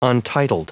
Prononciation du mot untitled en anglais (fichier audio)